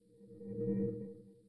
Windows NT 8.0 Beta Shutdown.wav